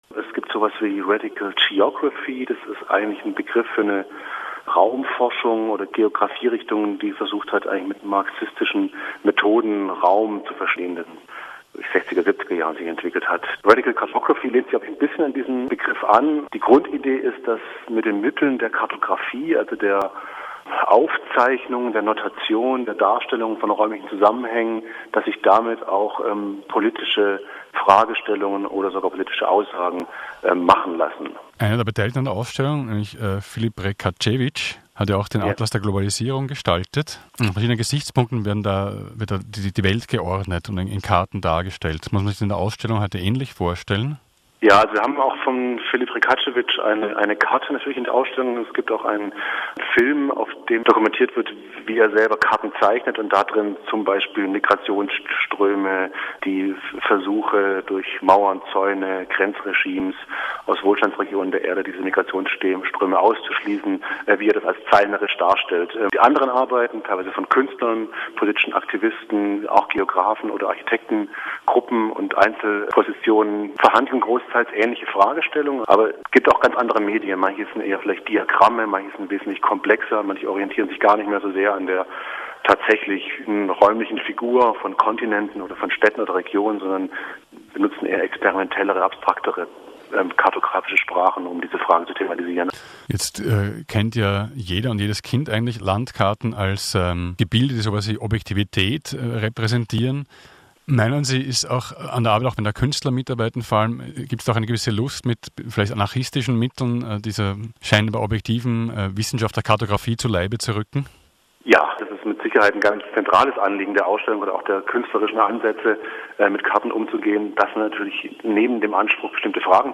Mensch kennt den Atlas der Globalisierung, der politisch relevante Informationen auch geopgrphisch aufarbeitet. Ein Interview